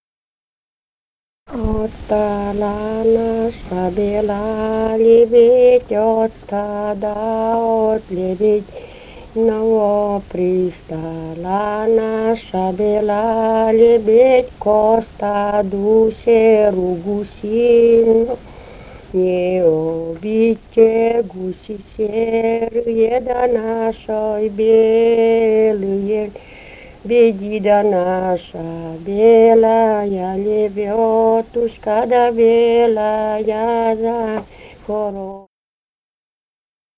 Живые голоса прошлого 060. «Отстала наша бела лебедь» (свадебная).